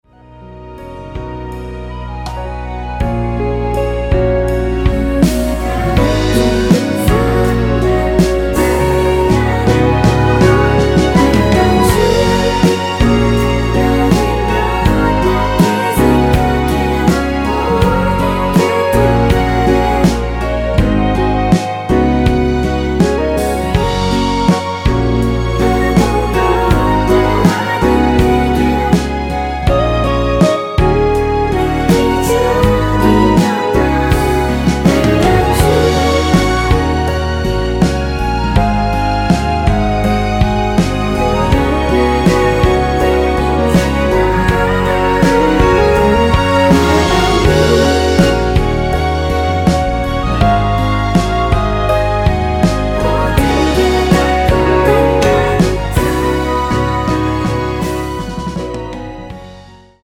원키에서(+4)올린 멜로디와 코러스 포함된 MR입니다.(미리듣기 확인)
F#
앞부분30초, 뒷부분30초씩 편집해서 올려 드리고 있습니다.
중간에 음이 끈어지고 다시 나오는 이유는